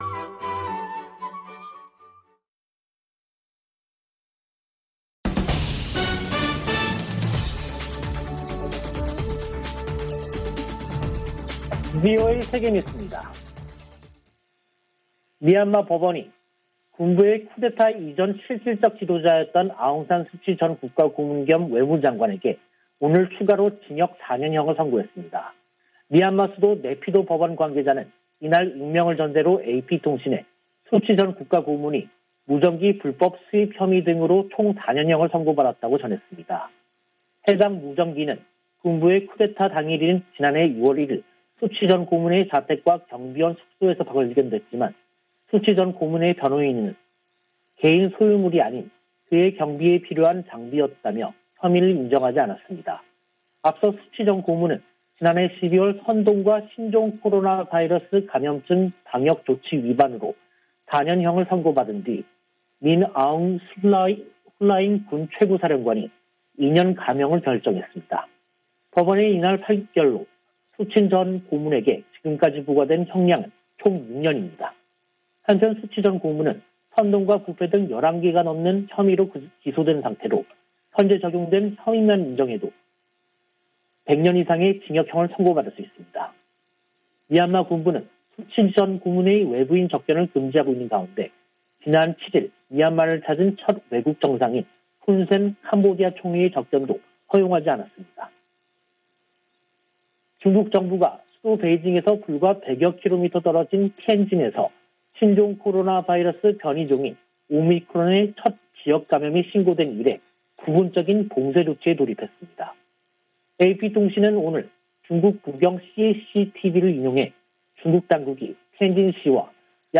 VOA 한국어 간판 뉴스 프로그램 '뉴스 투데이', 2022년 1월 10일 2부 방송입니다. 미국 등 5개국이 북한의 미사일 발사에 대한 유엔 안보리 협의를 요청했다고 미 국무부가 밝혔습니다. 북한 극초음속 미사일을 방어하기 위해서는 초기 탐지 능력이 중요하다고 미국의 전문가들은 지적했습니다. 지난해 10월 북한에 들어간 지원 물자가 소독 등을 마치고 정부 배급소에 도착했다고 유엔이 확인했습니다.